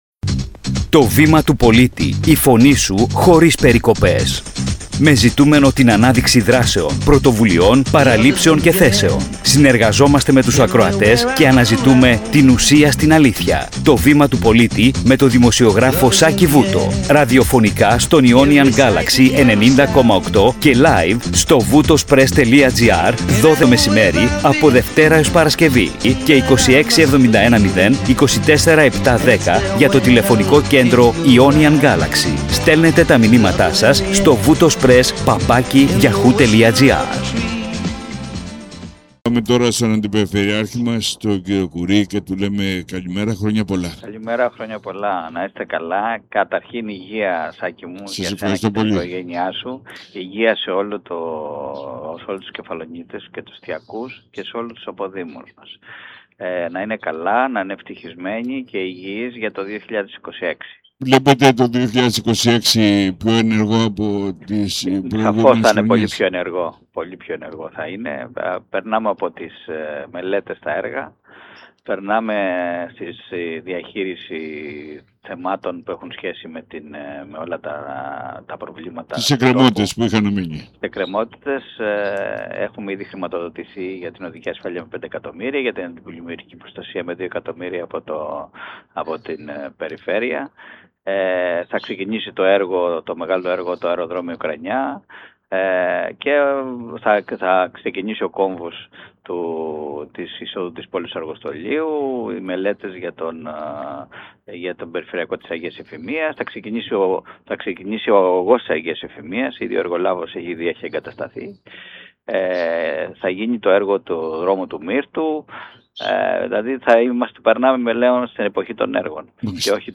Ο Αντιπεριφερειάρχης Κεφαλονιάς & Ιθάκης Σωτήρης Κουρής, μιλώντας στην εκπομπή Το Βήμα του Πολίτη στον Ionian Galaxy 90.8, έστειλε ξεκάθαρο μήνυμα για τη νέα χρονιά: το 2026 θα είναι χρονιά υλοποίησης έργων.
Από την εκπομπή «Το Βήμα του Πολίτη» στον Ionian Galaxy 90.8